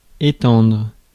Ääntäminen
IPA: /e.tɑ̃dʁ/